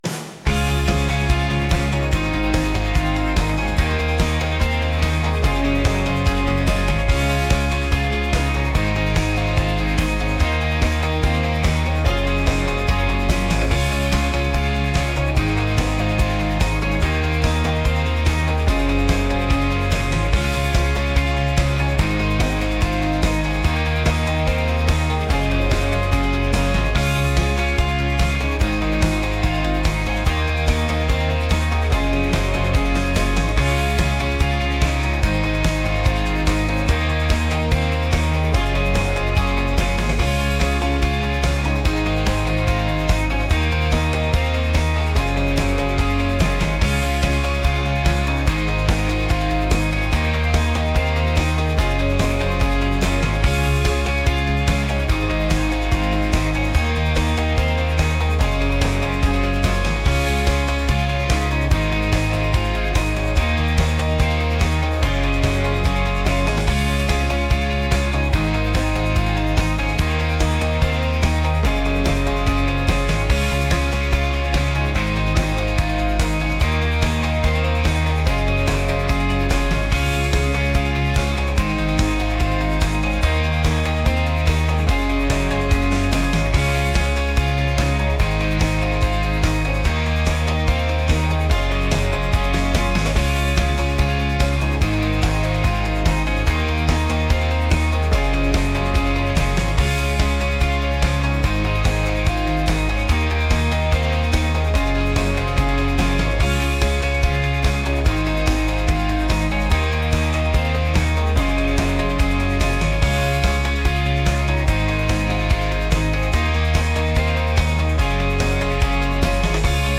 pop | upbeat | energetic